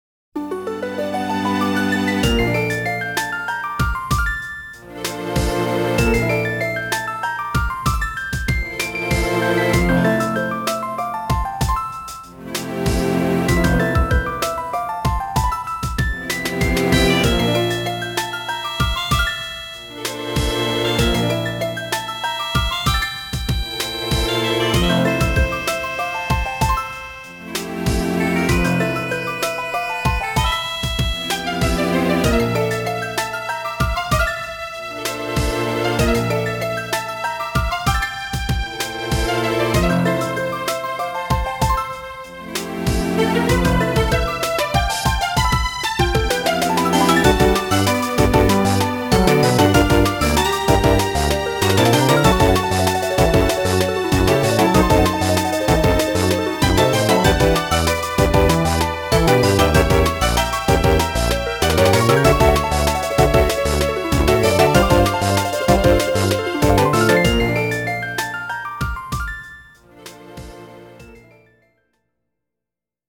applause2.mp3